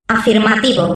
voz nș 0143